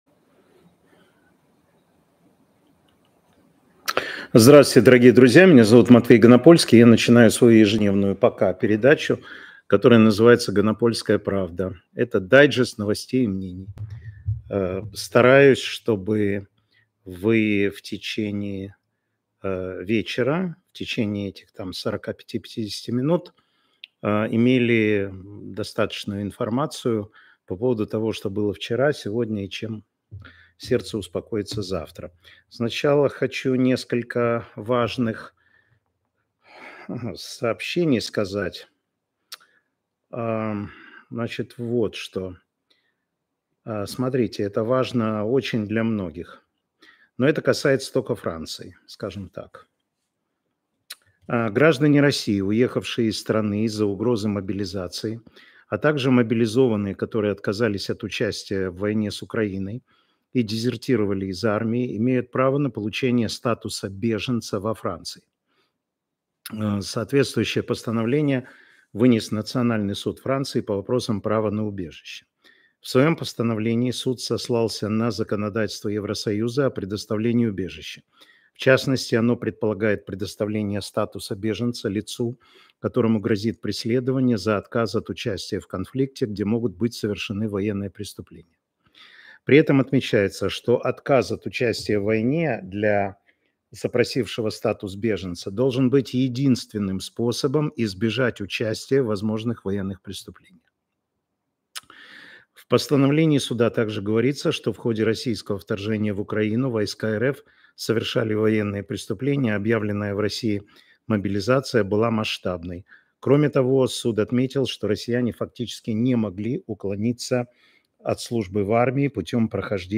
Эфир Матвея Ганапольского.